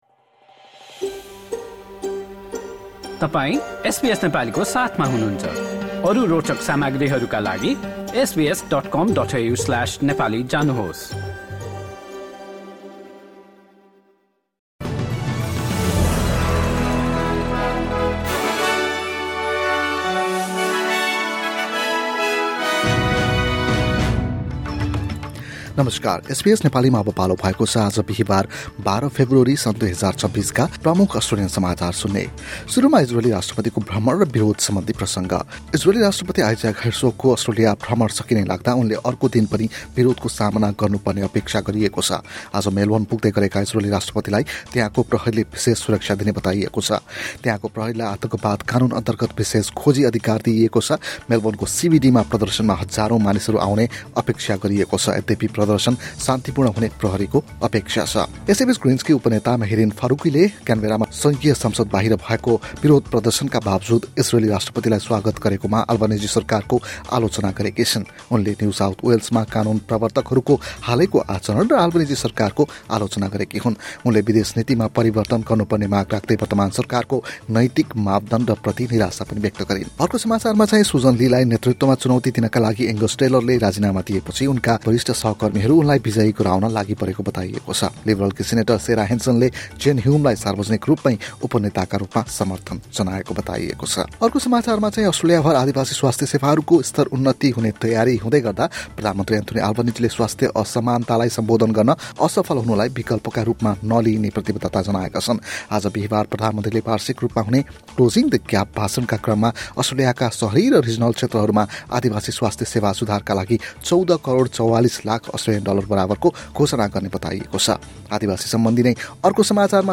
SBS Nepali Australian News Headlines: Thursday, 12 February 2026